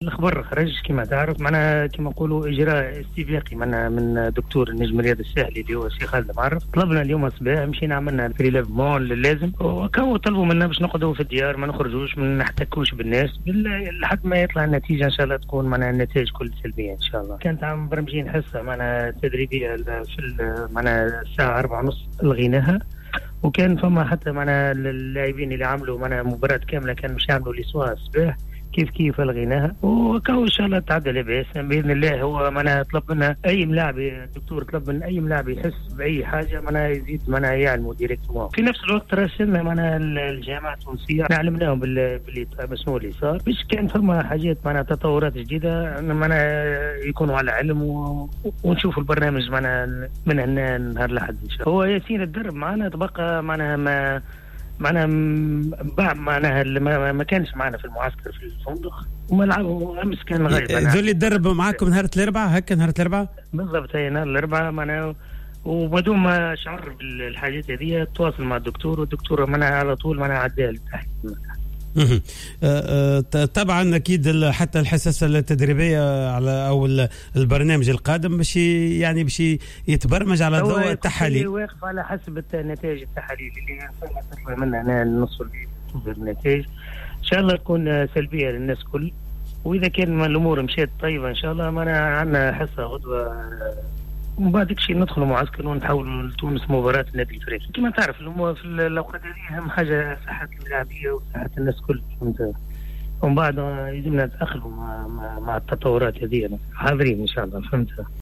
خلال مداخلته اليوم الجمعة في "جوهرة سبور"